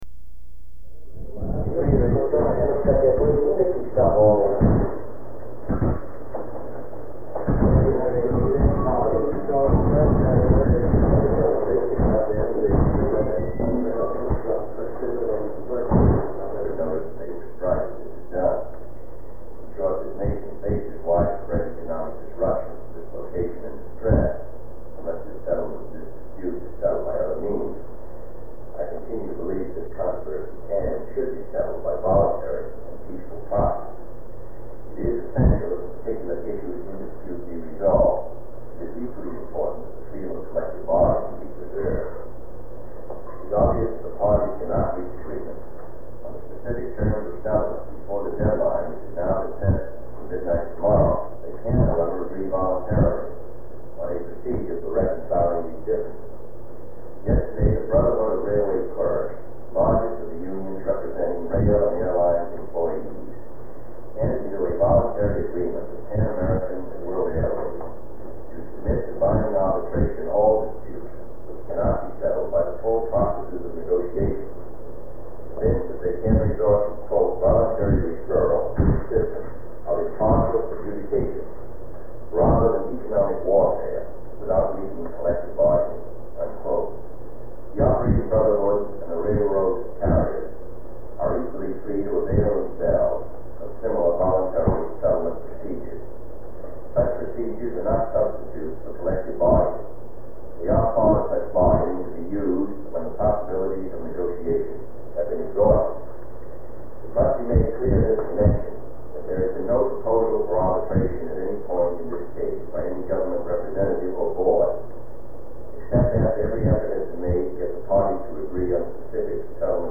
At the end of the recording, there are office noises and hallway conversations for about ten minutes.
Secret White House Tapes